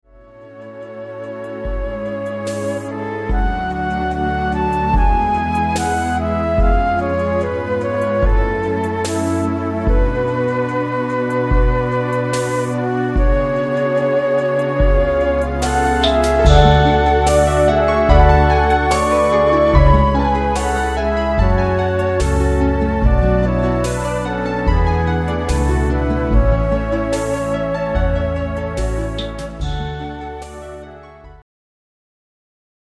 メ　イ　ン　リ　ー　ド　の　キ　ー　を　５　オ　ク　タ　ー　ブ　メ　イ　ン　で　制　作　し　て　し　ま　っ　た　よ　奥　　さ　ん　（　何
つまり一番上は６オクターブが出てくるわけで
あまりメロディーはいじりたくはなかったのですが、雛形ではボーカルパートとしてはキーを変更しても高すぎるor低すぎる音があったので、当初のものから若干手を加えて現在に至ってます。